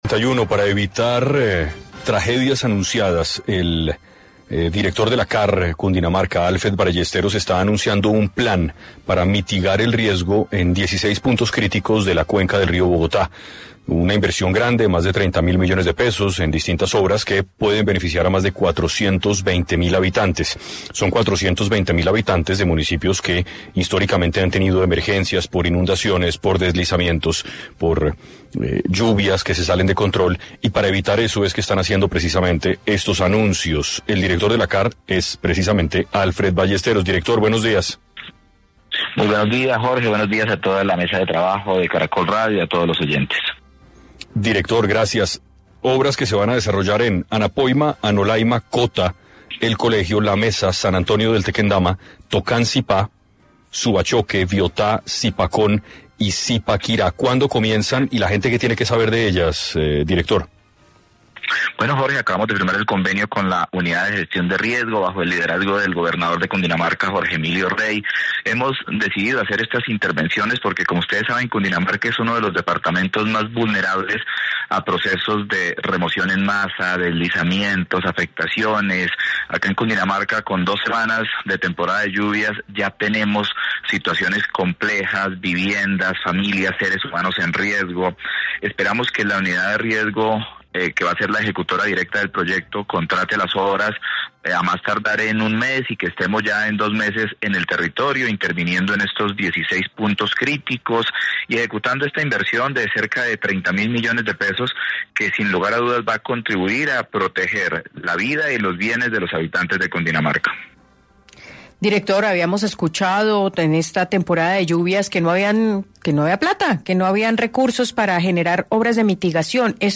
Alfred Ballesteros, director de la CAR Cundinamarca, estuvo en 6AM de Caracol Radio y explicó por qué es fundamental este plan para evitar tragedias a futuro.